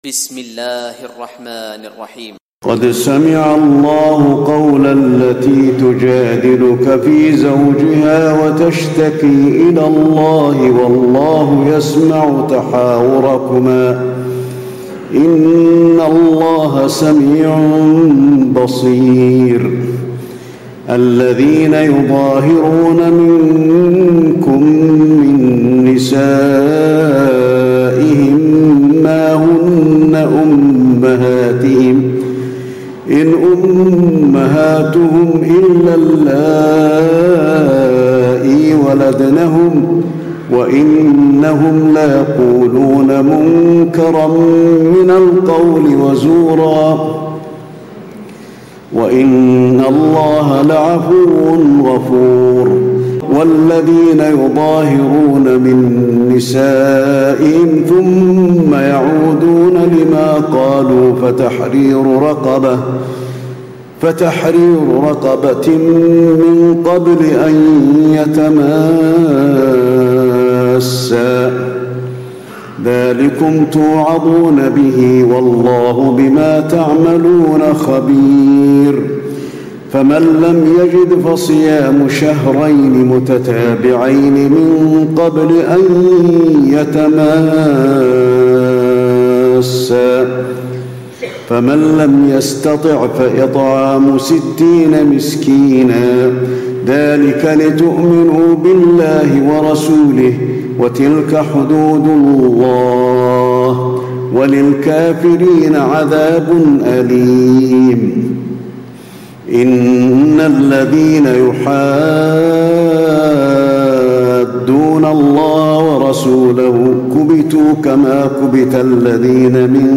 تراويح ليلة 27 رمضان 1436هـ من سورة المجادلة الى الممتحنة Taraweeh 27 st night Ramadan 1436H from Surah Al-Mujaadila to Al-Mumtahana > تراويح الحرم النبوي عام 1436 🕌 > التراويح - تلاوات الحرمين